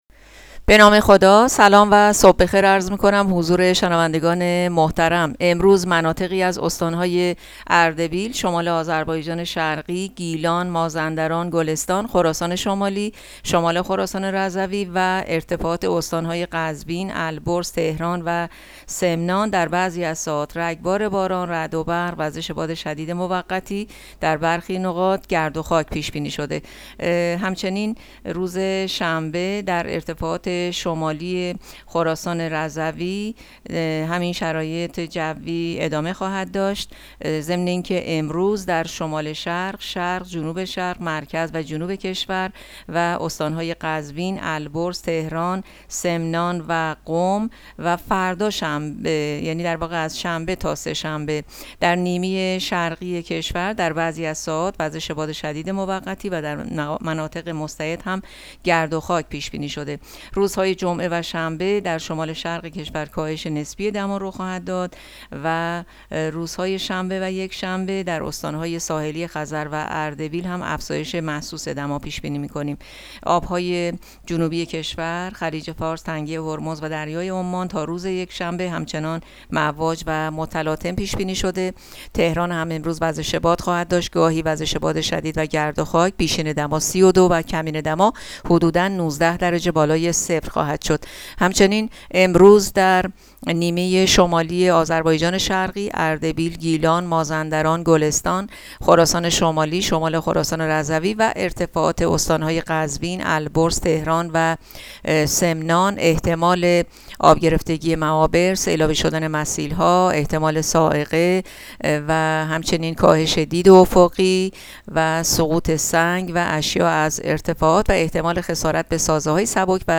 گزارش رادیو اینترنتی پایگاه‌ خبری از آخرین وضعیت آب‌وهوای ۲۶ اردیبهشت؛